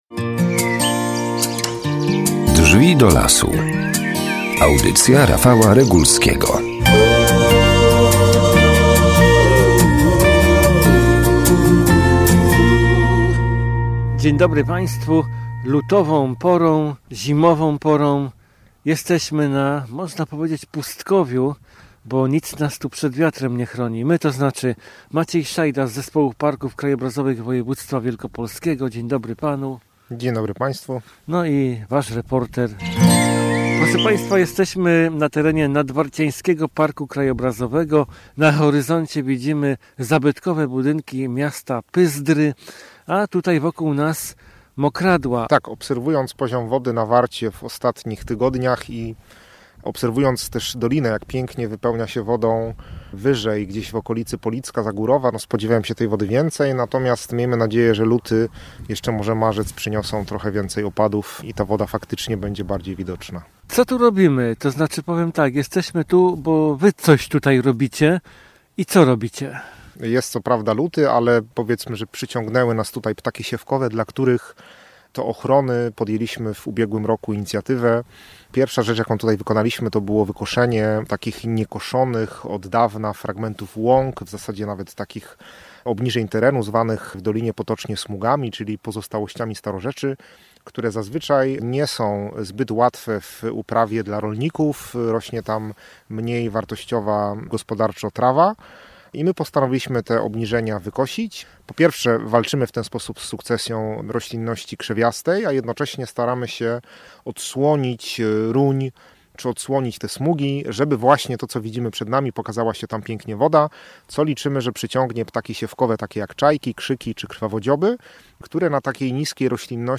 Nasz cotygodniowy spacer na łonie natury nie zawsze - mimo tytułu audycji - odbywa się w lesie.